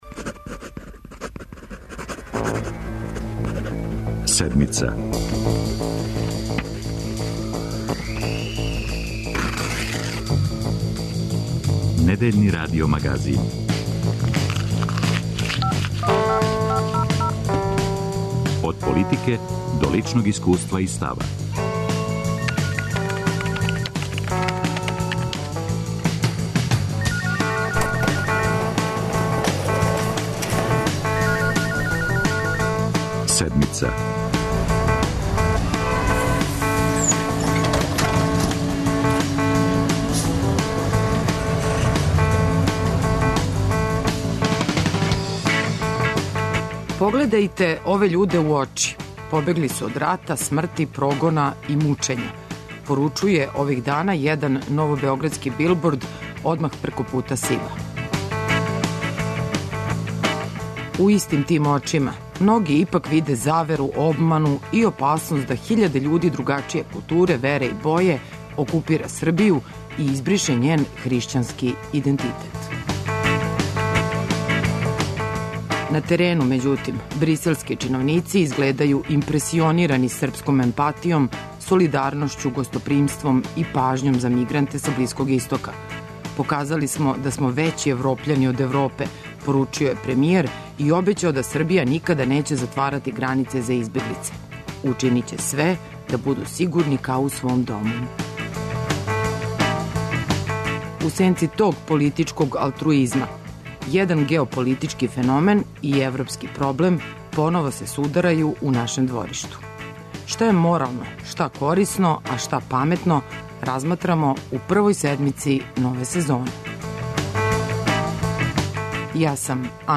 преузми : 26.81 MB Седмица Autor: разни аутори Догађаји, анализе, феномени.